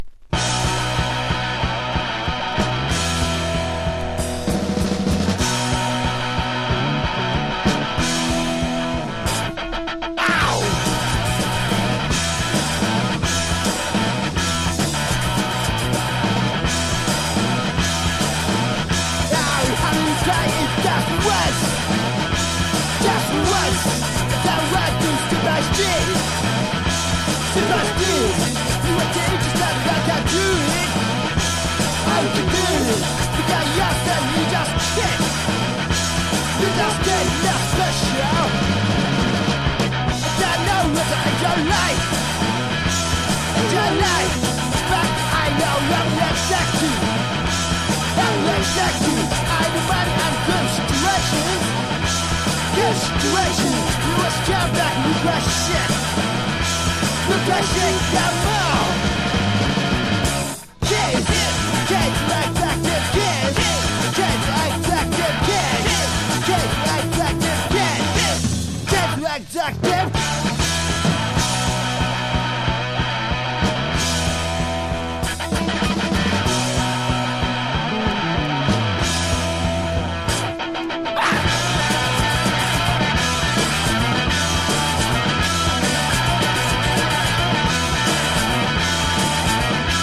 前作よりパワー・ポップに接近した大傑作アルバム。
PUNK / HARDCORE# 90-20’S ROCK